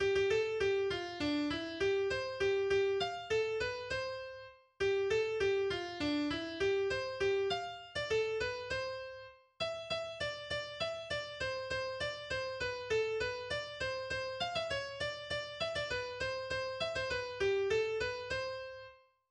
schweizerisches Volkslied